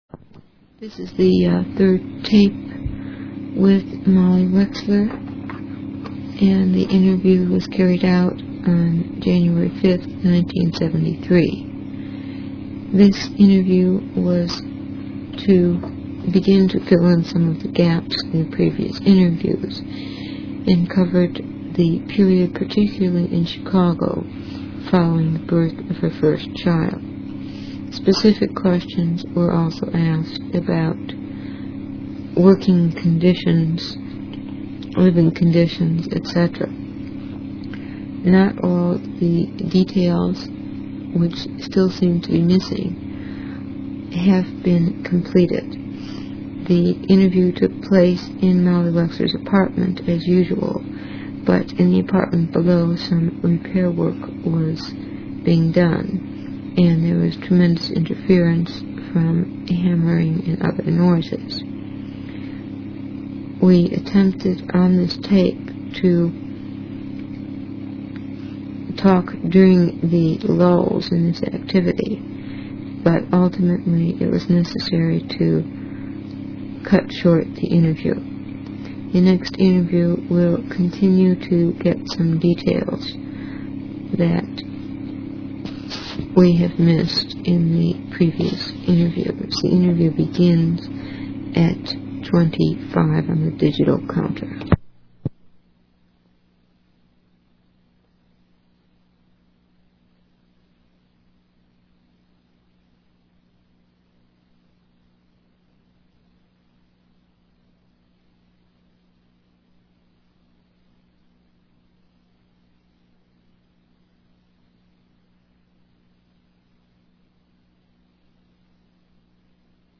This resulted in some background noise. Initially we tried to talk during lulls in the pounding, but ultimately, it was necessary to cut the interview short.